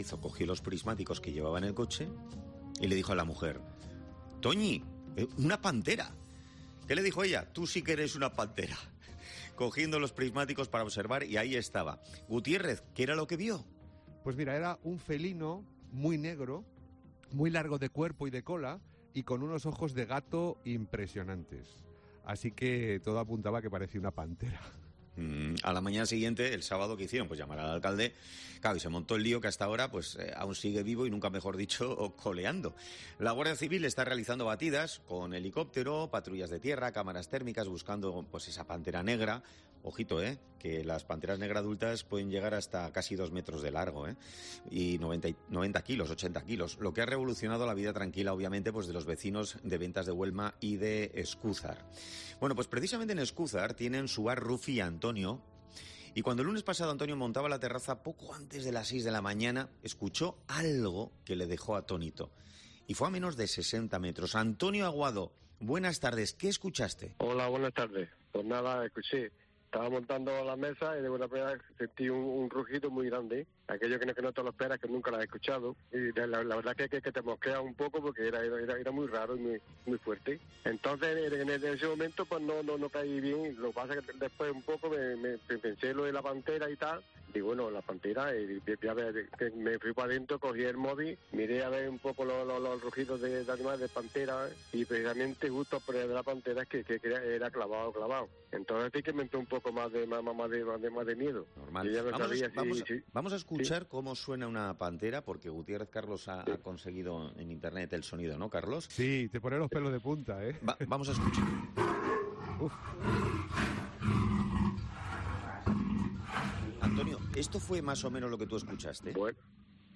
El testimonio de un vecino que oyó a la pantera en Escúzar